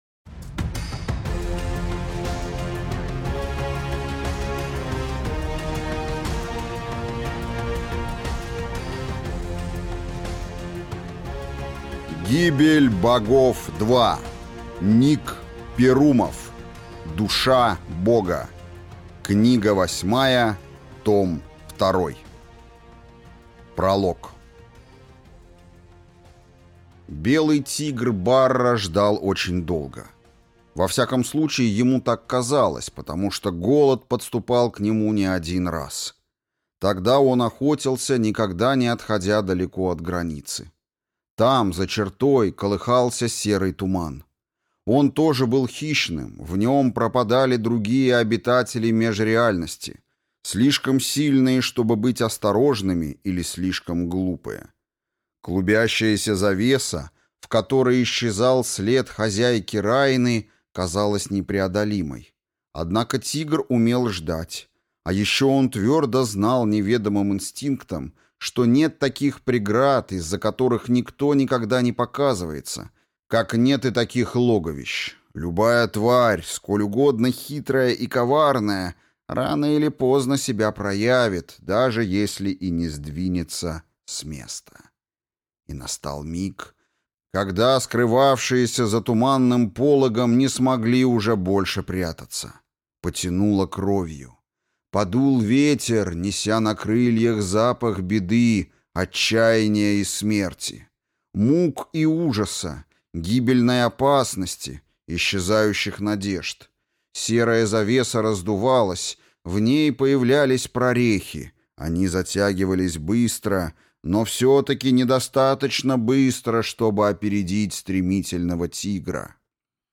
Аудиокнига Душа Бога.